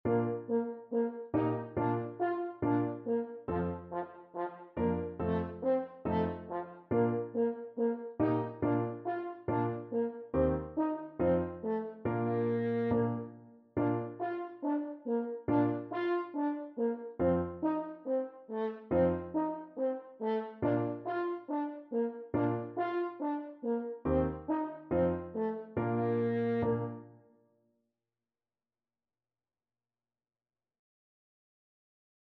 4/4 (View more 4/4 Music)
Fast = c. 140
F4-F5